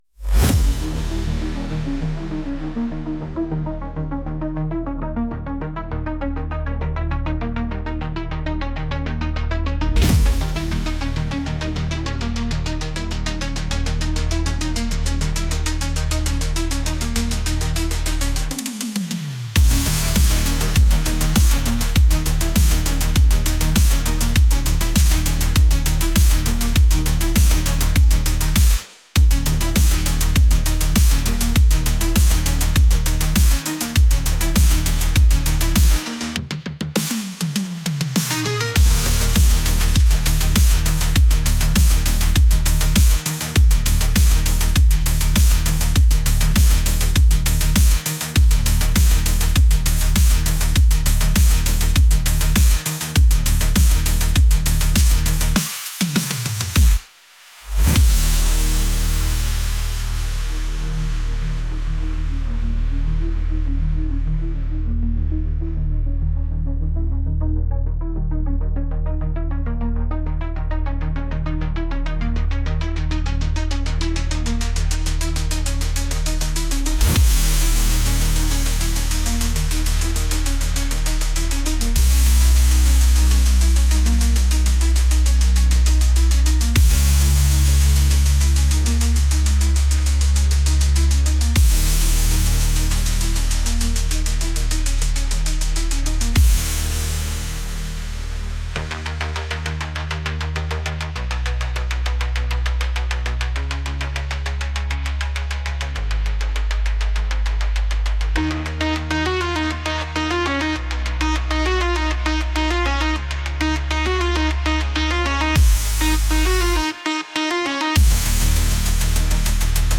electronic | energetic